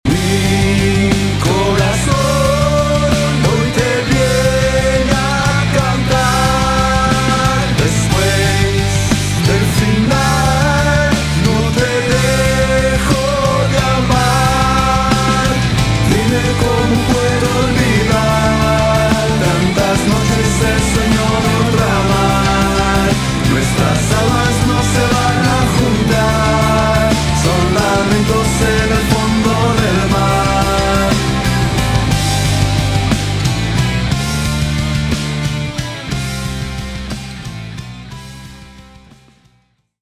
Género: Rock / Alternative.